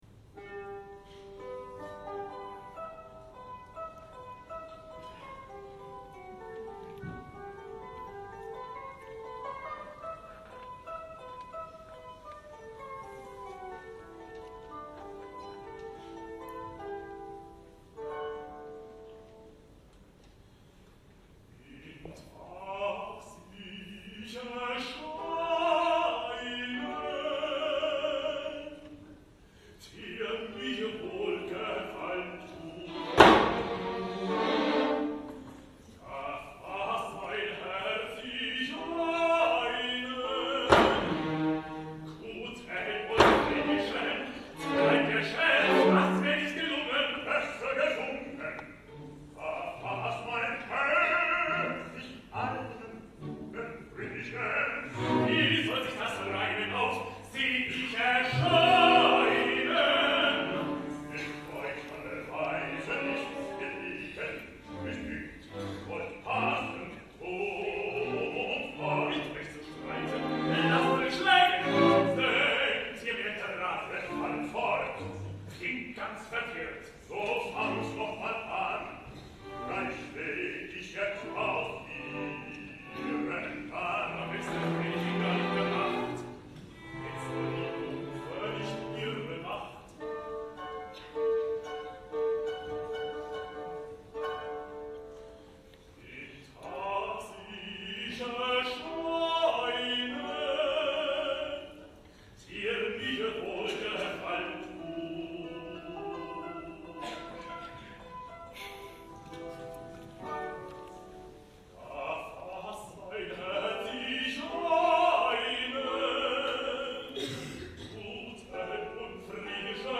WerbeliedDemo.mp3